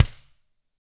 Index of /90_sSampleCDs/ILIO - Double Platinum Drums 2/Partition A/GRETSCHKICKD
GRCHKICK2D-R.wav